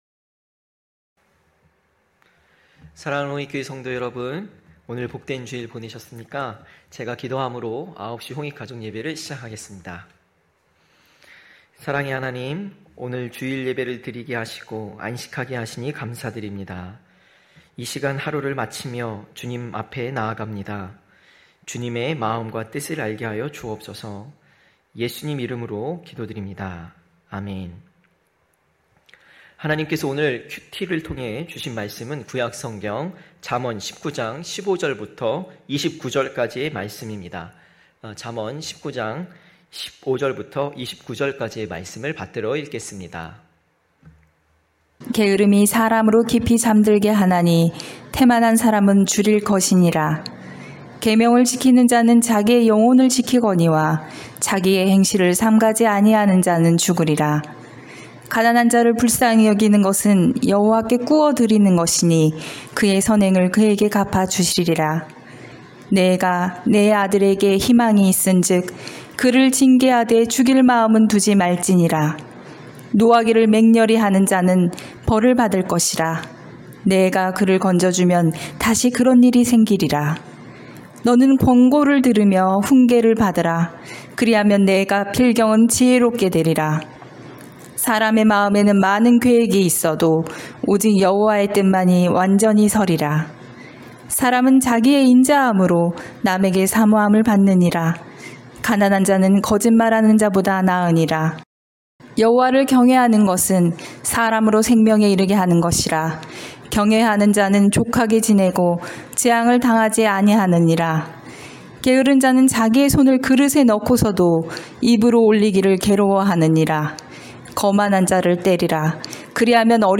9시홍익가족예배(6월6일).mp3